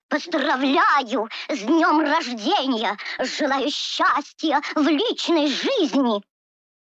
• Качество: высокое
Здесь вы найдете знаменитые песни, смешные диалоги и фразы персонажей в отличном качестве.